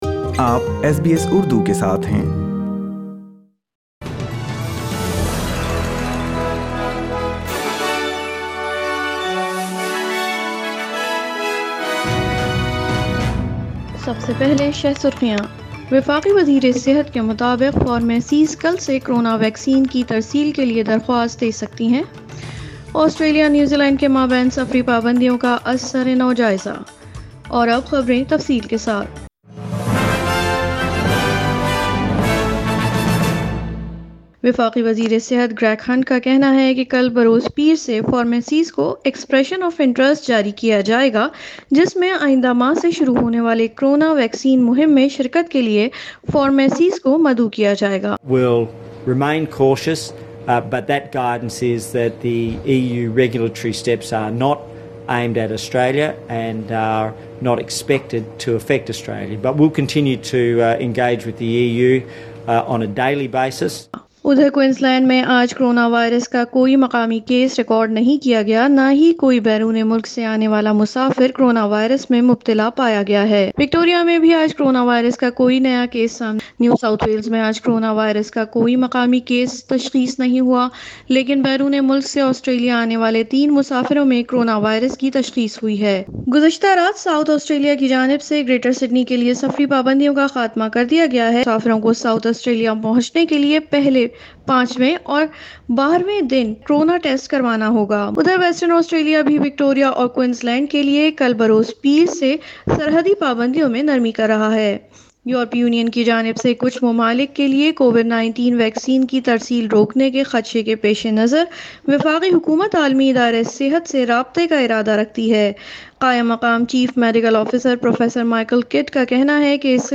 اردو خبریں اتوار 31 جنوری 2021